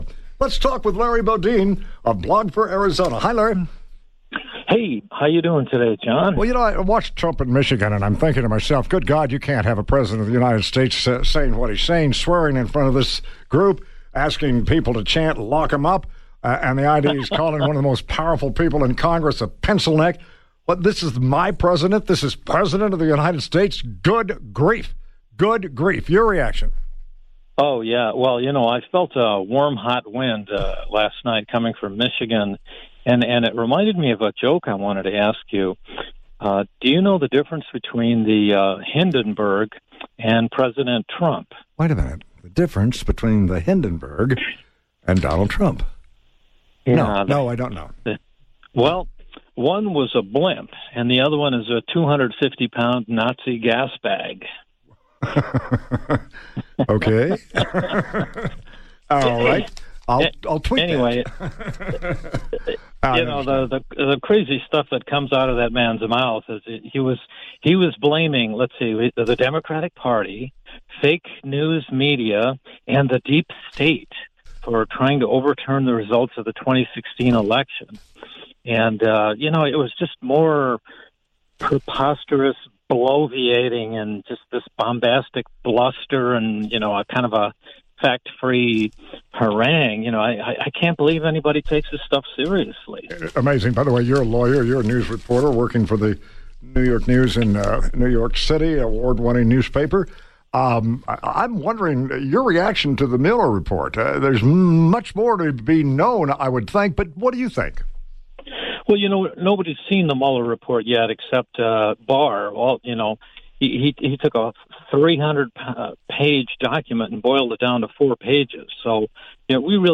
He invited me on his show to talk about what a person could do in the face of this preposterous bloviating, the bombastic bluster and the fact-free harangue.